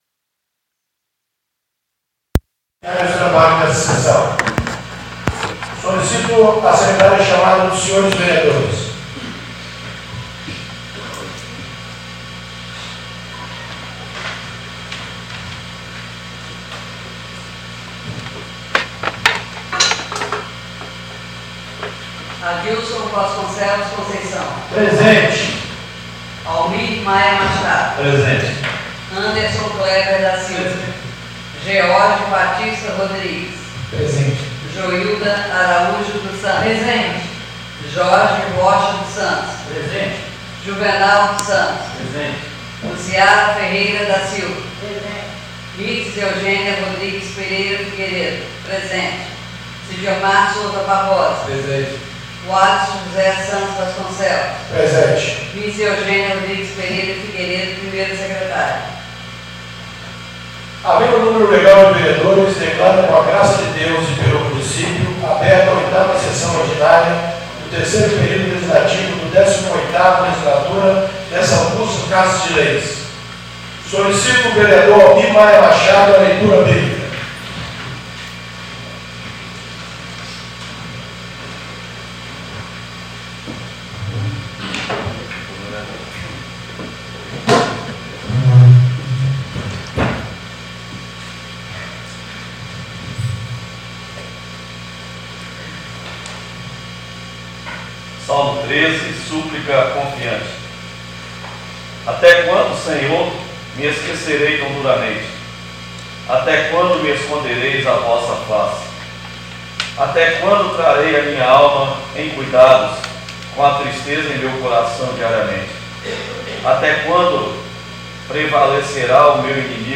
8ª Sessão Ordinária do dia 06 de junho de 2019